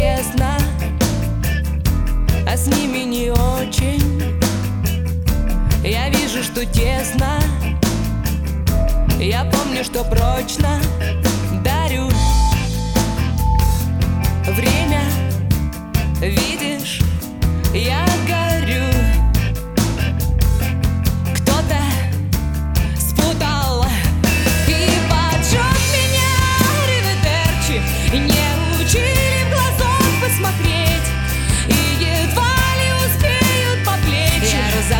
Жанр: Русская поп-музыка / Русский рок / Русские